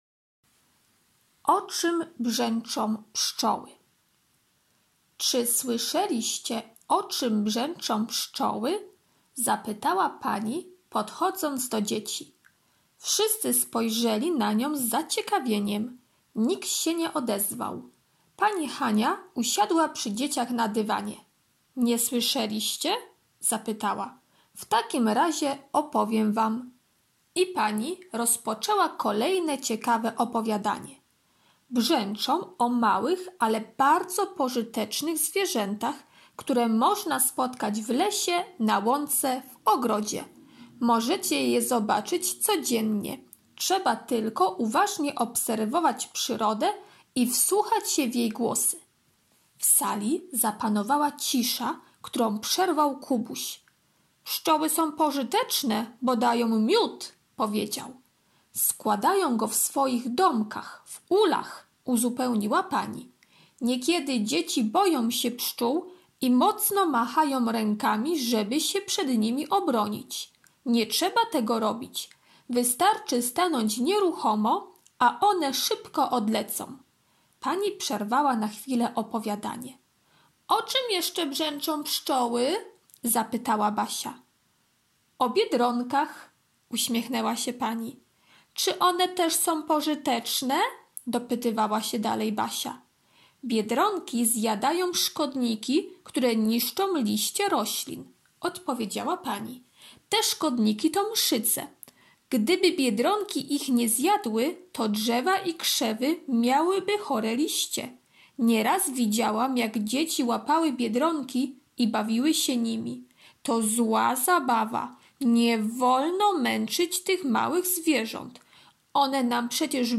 środa - opowiadanie "O czym brzęczą pszczoły" [6.78 MB] środa - prezentacja "Pszczoła" [1.00 MB] środa - ćw. dla chętnych - pisanie litery F, f [24.33 kB] środa - ćw. dla chętnych "Pszczoła" [226.71 kB]